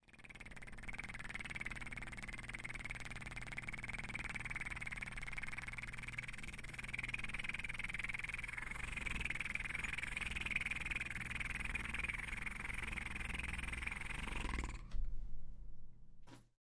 有鸟的牛棚
描述：一个24bit 29khz的立体声牛棚录音。有牛的叫声，鸟的鸣叫和远处的拖拉机声。
标签： 谷仓 拖拉机 农场 鸣叫 农场 农场
声道立体声